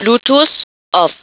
2.  Appuyez pour ouvrir ou fermer le Bluetooth (la voix confirme,  il dit "Blue Tooth On" ou "